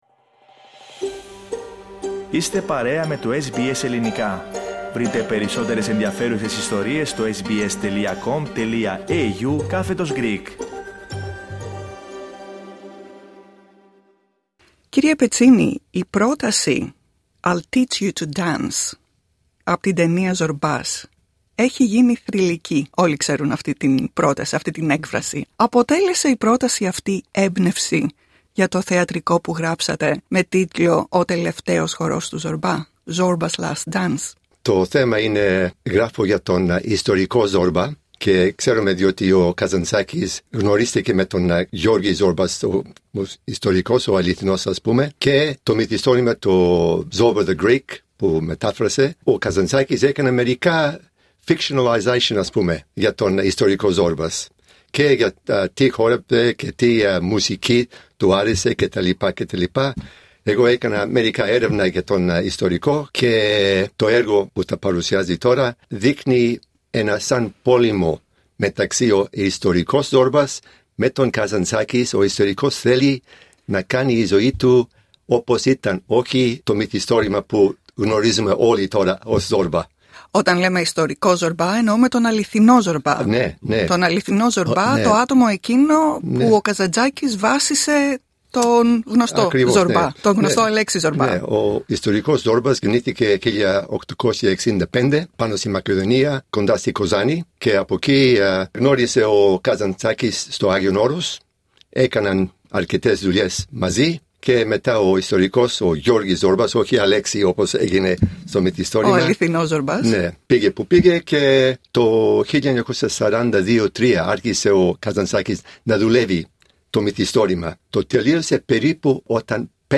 Στην συνέντευξη που ακολουθεί οι τρεις βασικοί συντελεστές του έργου μιλούν για την ανάπτυξη του έργου και τις προκλήσεις που αντιμετώπισαν δεδομένου ότι υποδύονται δύο χαρακτήρες ο καθένας και ενώνουν εποχές που βρίσκονται έναν αιώνα μακριά.